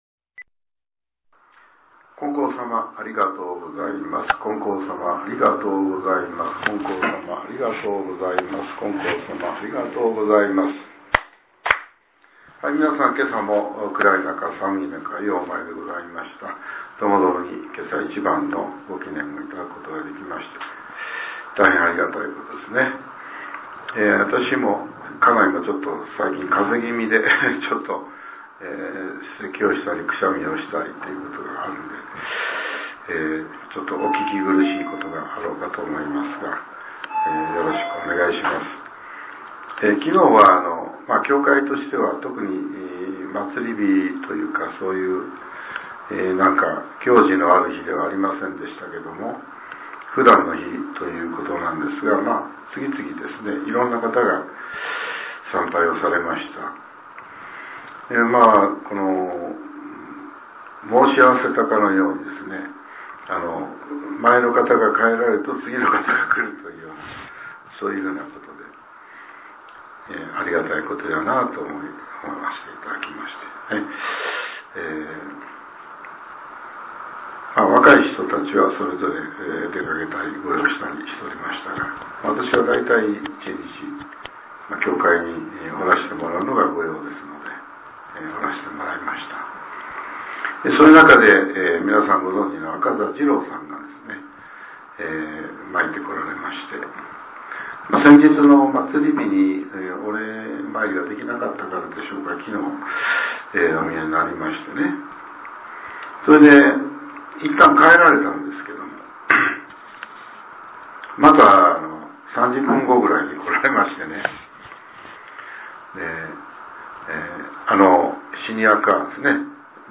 令和８年２月１６日（朝）のお話が、音声ブログとして更新させれています。